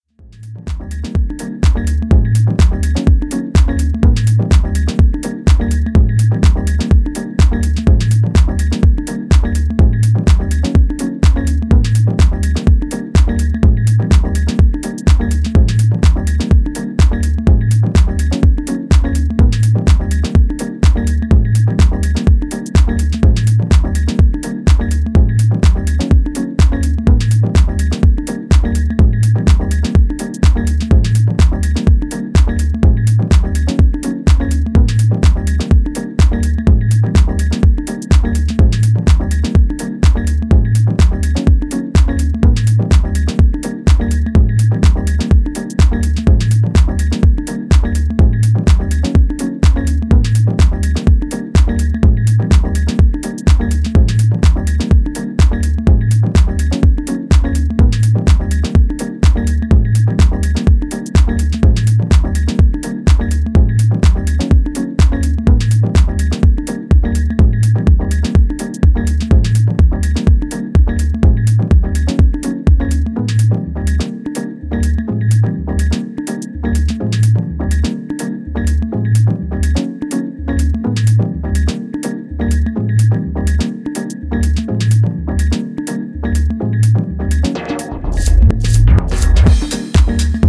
Styl: House, Techno, Breaks/Breakbeat Vyd�no